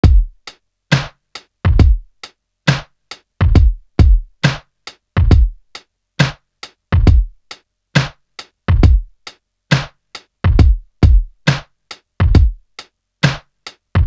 Simmons Drum.wav